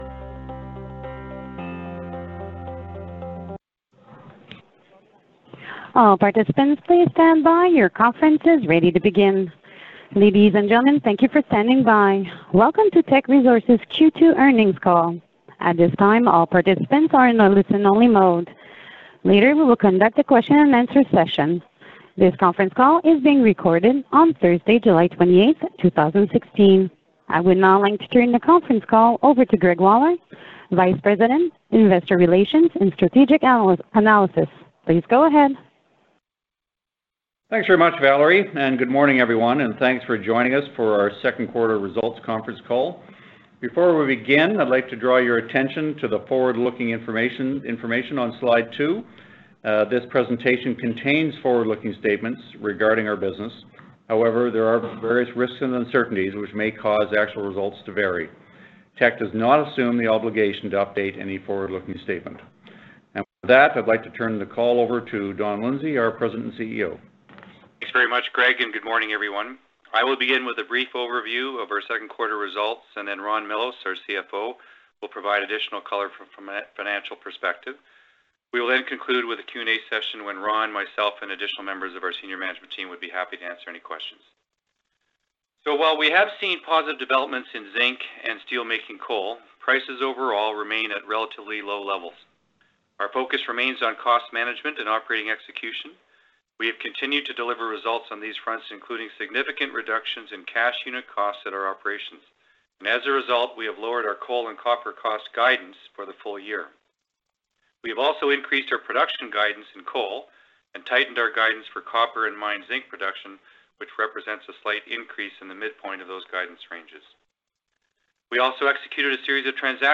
Q2 2016 Financial Report [PDF - 2.09 MB] Q2 2016 Financial Report Presentation Slides [PDF - 1.70 MB] Q2 2016 Quarterly Report Conference Call Transcript [PDF - 0.16 MB] Q2 2016 Quarterly Report Conference Call Audio [MP3 - 39.23 MB]